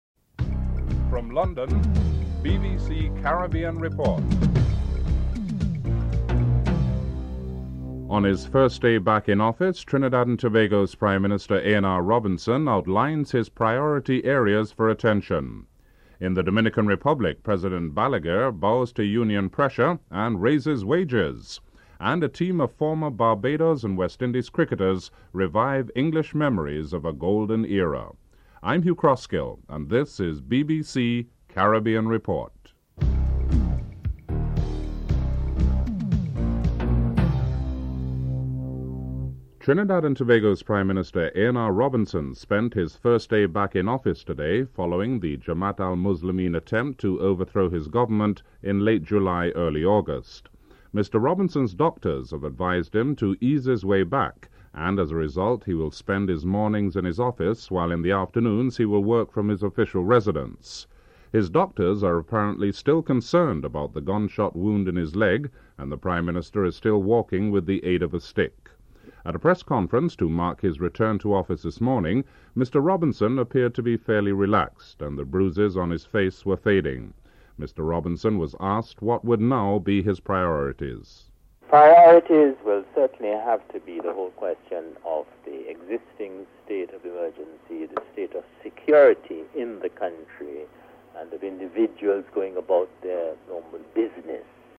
1. Headlines (00:00-00:38)
2. A.N.R. Robinson, Prime Minister of Trinidad and Tobago, outlines his political priorities on his first day back in office following the attempted coup. Excerpt of a press conference features comments by A.N.R. Robinson (00:39-02:12)